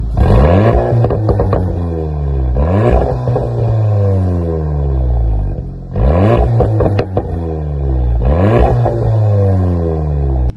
Golf-VII-GTI-TCR-Stand.mp3